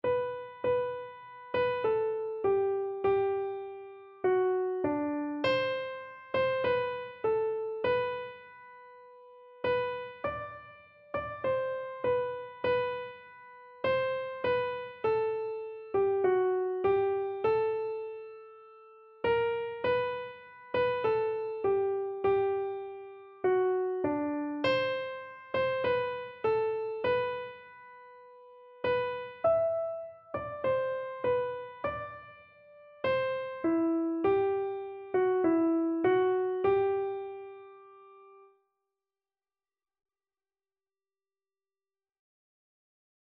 Free Sheet music for Keyboard (Melody and Chords)
4/4 (View more 4/4 Music)
G major (Sounding Pitch) (View more G major Music for Keyboard )
Keyboard  (View more Easy Keyboard Music)
Classical (View more Classical Keyboard Music)